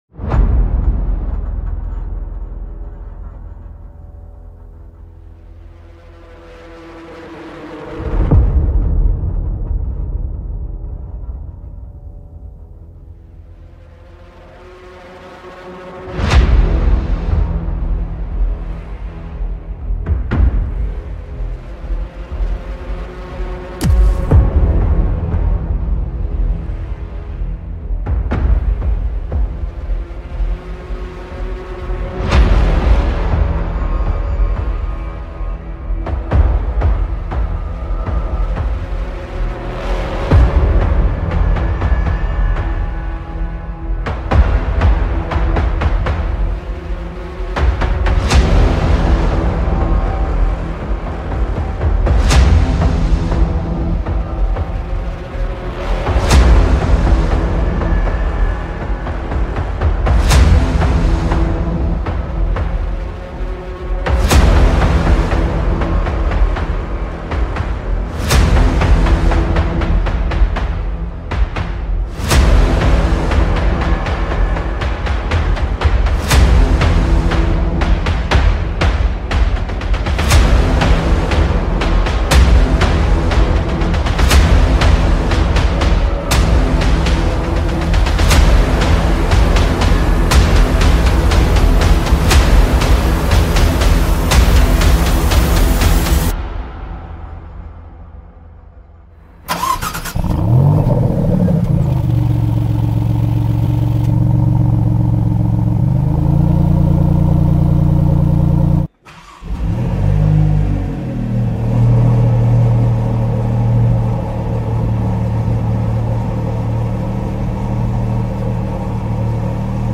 2023 Maserati MC20 - Wild Super Sports Car_